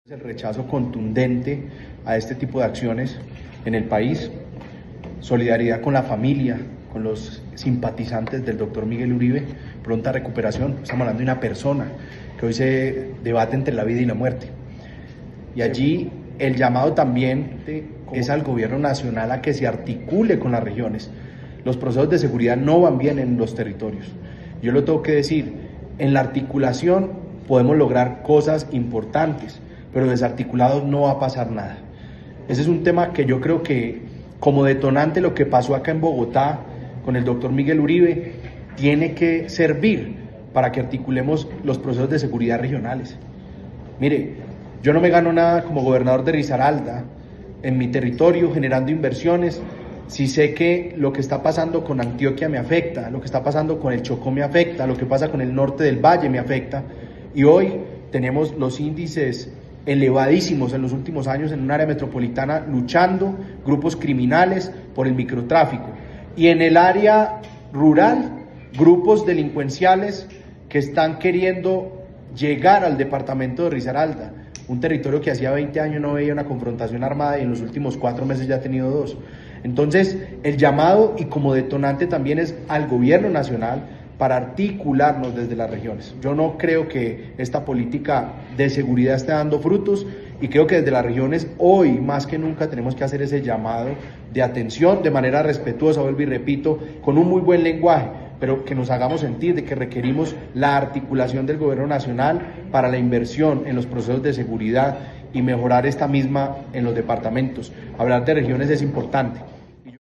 Con un mensaje claro, contundente y cargado de sentido regional, el gobernador de Risaralda, Juan Diego Patiño Ochoa, participó este lunes 9 de junio en la reunión de la Junta Directiva de la Federación Nacional de Departamentos (FND), en la que expresó su total rechazo al atentado contra el senador Miguel Uribe Turbay, pidió respeto en el discurso político y exigió al Gobierno Nacional articular de manera inmediata estrategias de seguridad con las regiones.
JUAN-DIEGO-PATINO-OCHOA-GOBERNADOR-RDA.mp3